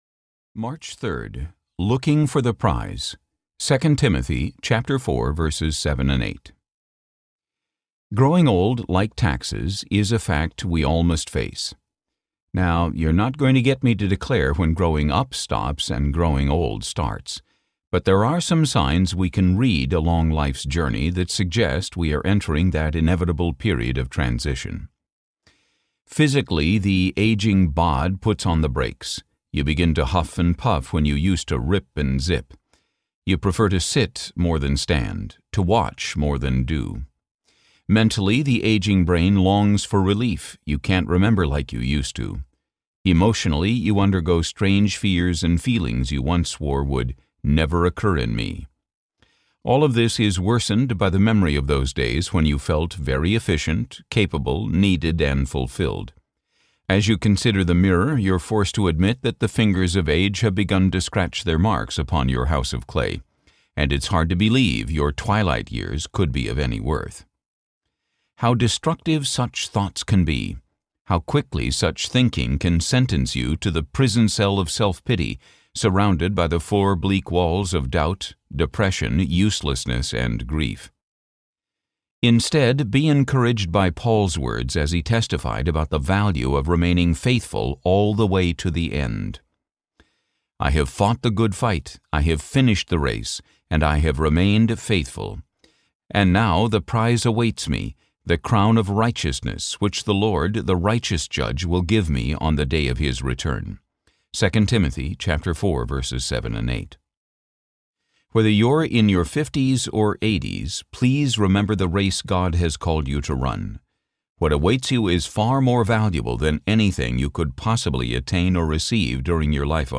Good Morning, Lord . . . Can We Talk? Audiobook
13.75 Hrs. – Unabridged